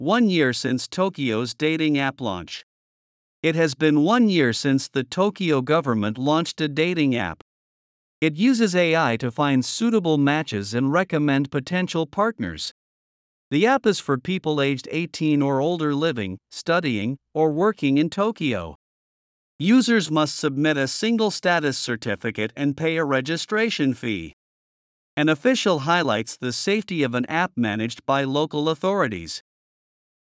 【スロースピード】